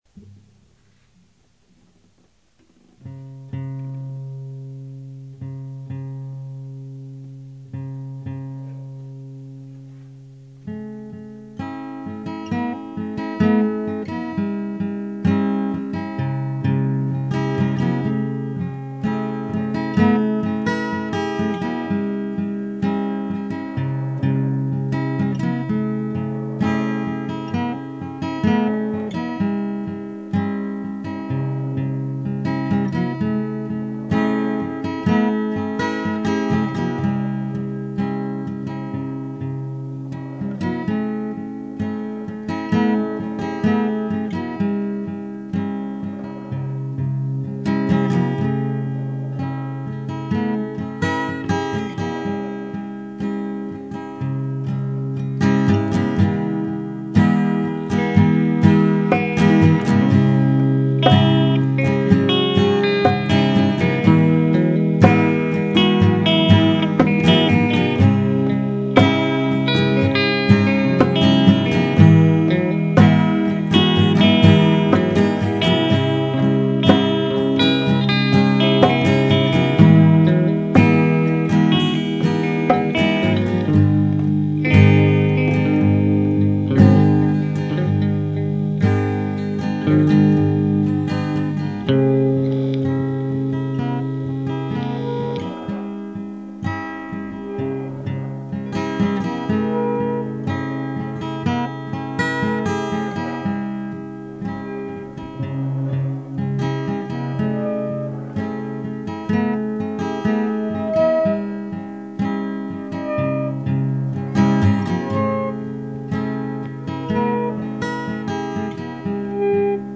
Majestueuse et organique
folk